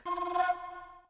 AoE2 prrrroh Sound Effect
prrroh.mp3